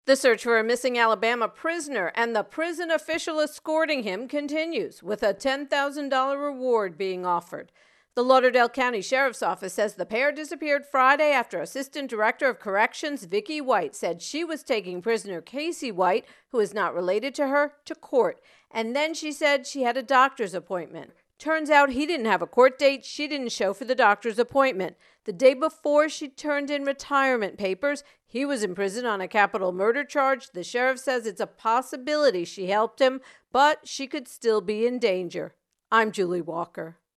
Alabama Missing Prison Official intro and voicer